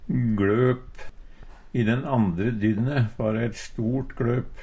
gLøp - Numedalsmål (en-US)